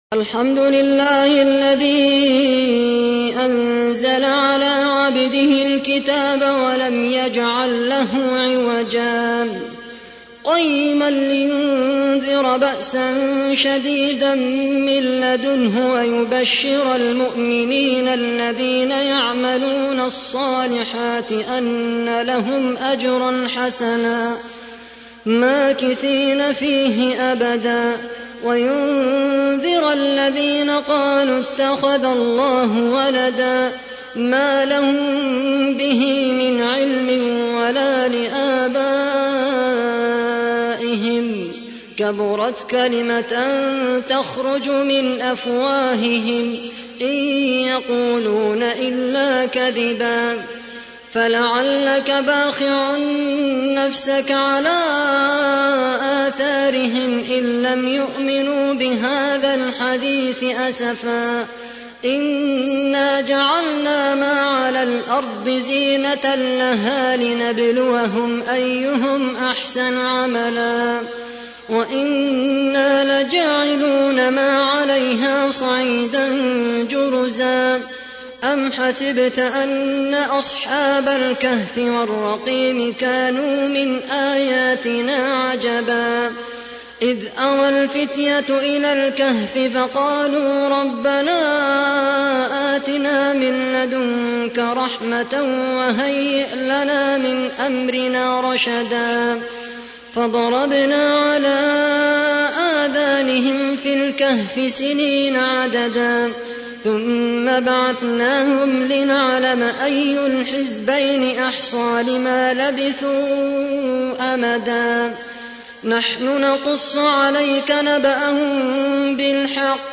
تحميل : 18. سورة الكهف / القارئ خالد القحطاني / القرآن الكريم / موقع يا حسين